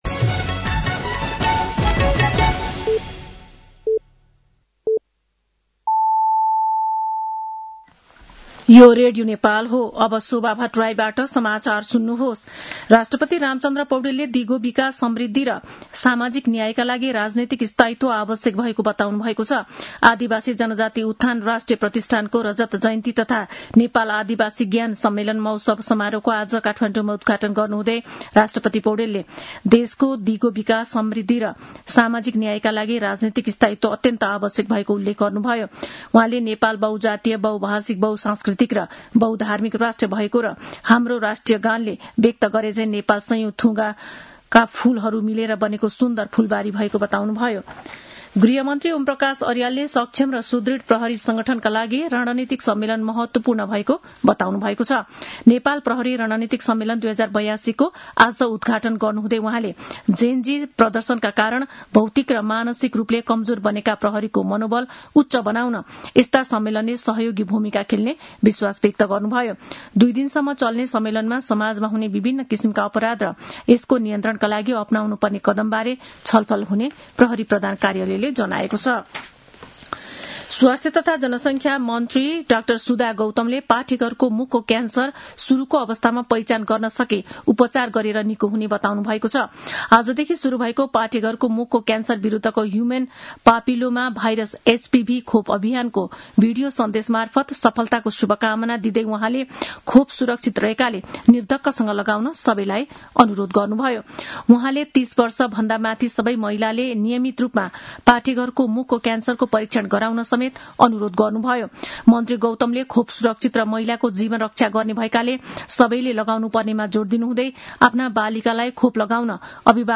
An online outlet of Nepal's national radio broadcaster
साँझ ५ बजेको नेपाली समाचार : २५ माघ , २०८२
5.-pm-nepali-news-1-3.mp3